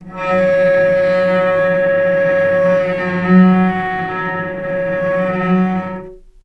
healing-soundscapes/Sound Banks/HSS_OP_Pack/Strings/cello/sul-ponticello/vc_sp-F#3-mf.AIF at b3491bb4d8ce6d21e289ff40adc3c6f654cc89a0
vc_sp-F#3-mf.AIF